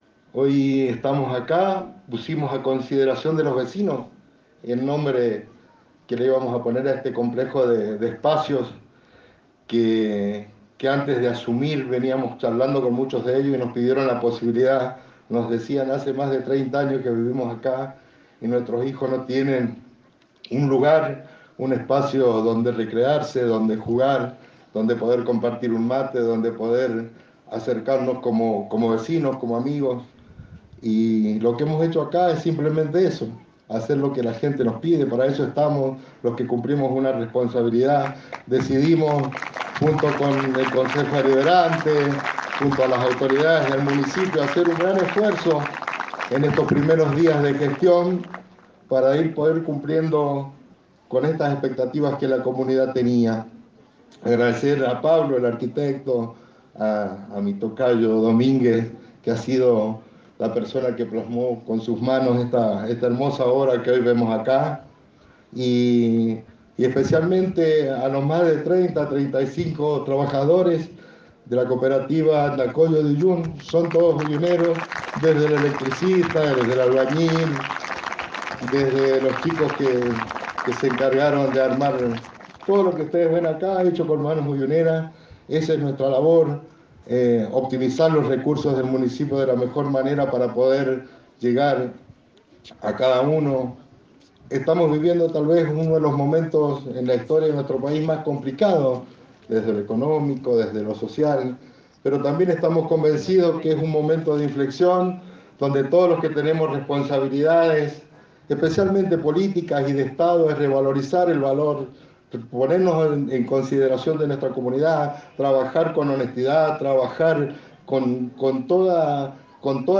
Intendente David Domínguez: